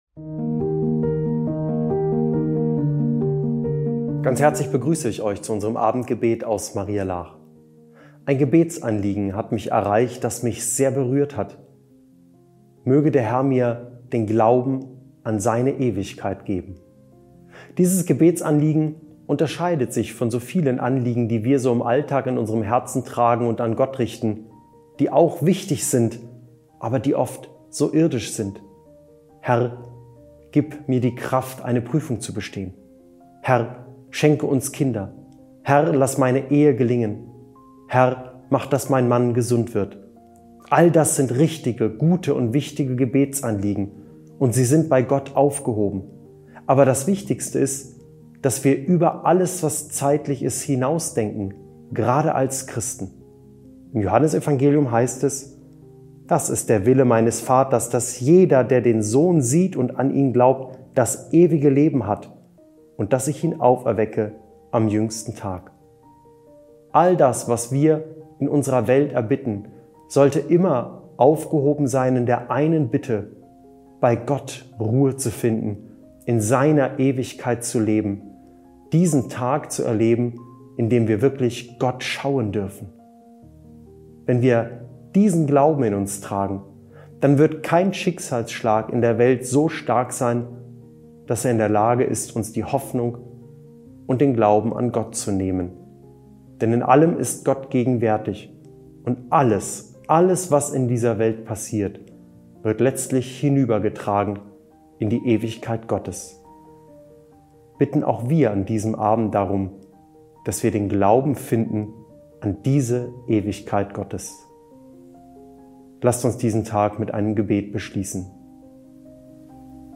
Abendgebet – 20. Januar 2026
Religion , Religion & Spiritualität